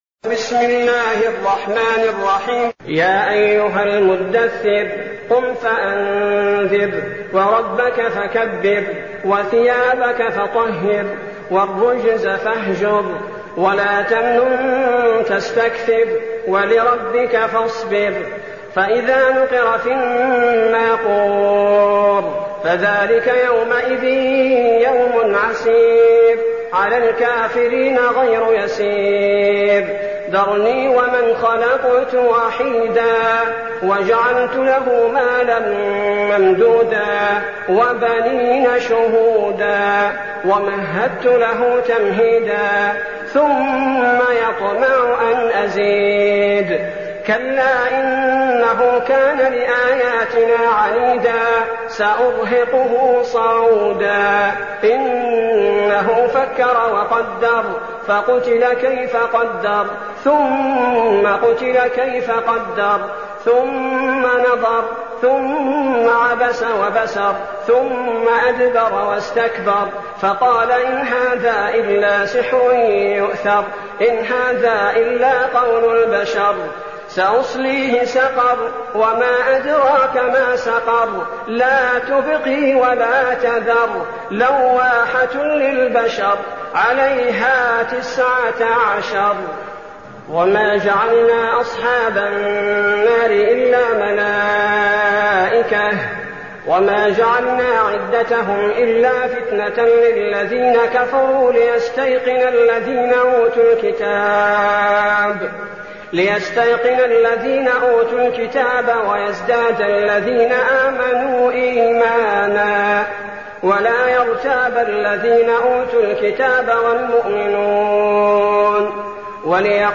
المكان: المسجد النبوي الشيخ: فضيلة الشيخ عبدالباري الثبيتي فضيلة الشيخ عبدالباري الثبيتي المدثر